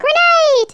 Grenade.wav